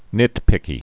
(nĭtpĭkē)